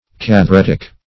Search Result for " catheretic" : The Collaborative International Dictionary of English v.0.48: Catheretic \Cath`e*ret"ic\, n. [Gr.